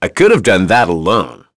Crow-Vox_Victory.wav